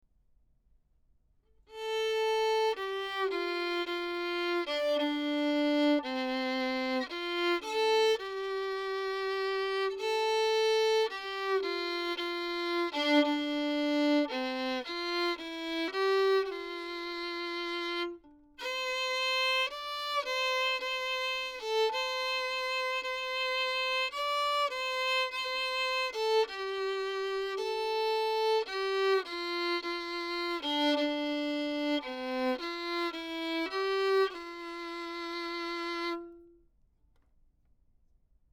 Type: Cardioid Condenser
Violin Lyric – 24cm from F hole:
sE-X1-S-Violin-Lyric-24cm-from-F-hole.mp3